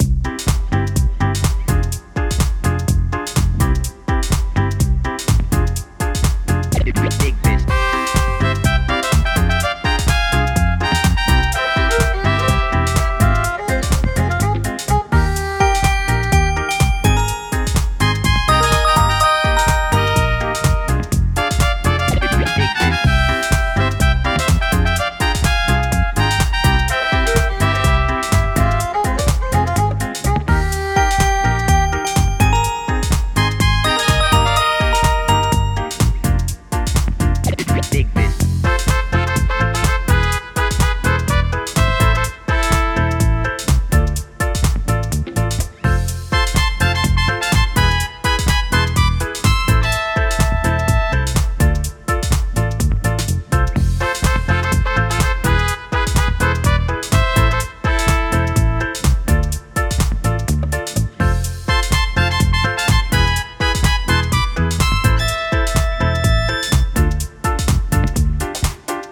明るい楽曲
【イメージ】夏、金管楽器（トランペットなど）、陽気 など